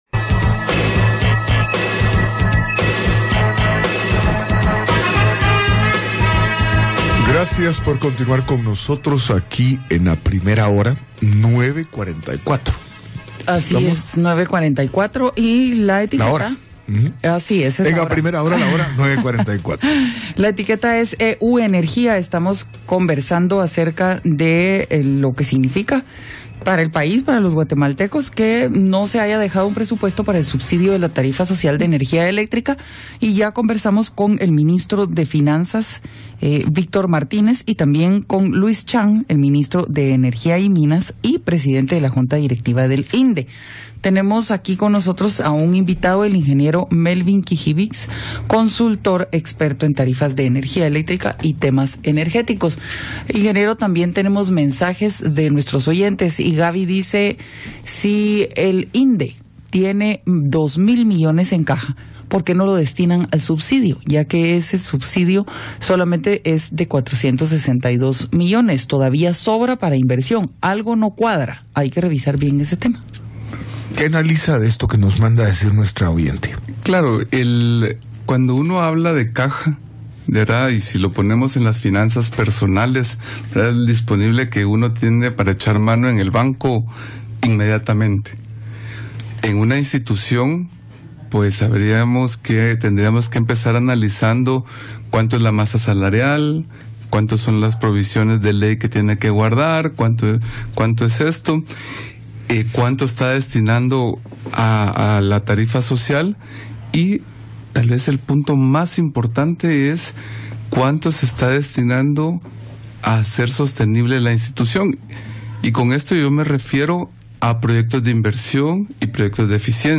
PATRULLAJE INFORMATIVO / EMISORAS UNIDAS Entrevista